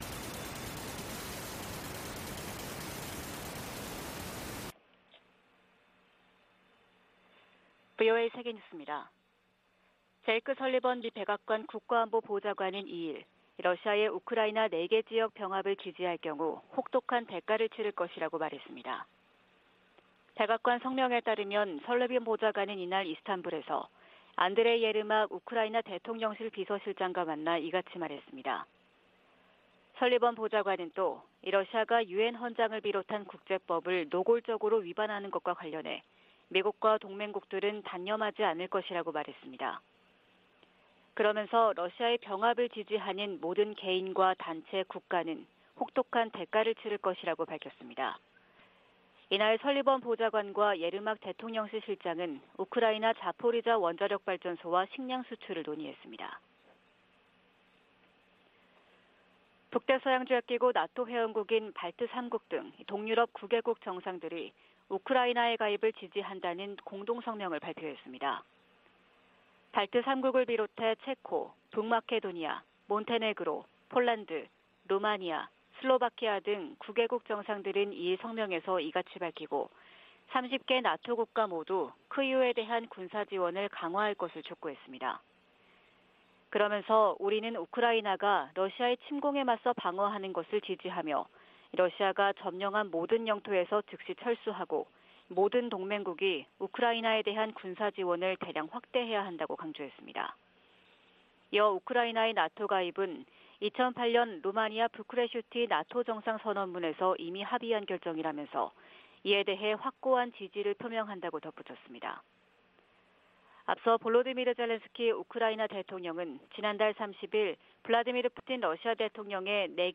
VOA 한국어 '출발 뉴스 쇼', 2022년 10월 4일 방송입니다. 북한이 지난 1일 동해상으로 탄도미사일(SRBM) 2발을 발사해 지난 달 25일 이후 총 7발의 미사일을 발사했습니다. 미 국무부는 잇따른 탄도미사일 발사로 안정을 흔드는 북한의 무기 역량을 제한하겠다는 의지를 나타냈습니다. 한국 탈북자 그룹이 또 신종 코로나바이러스 감염증 의약품 등을 매단 대형 풍선을 북한으로 보낸 것으로 알려졌습니다.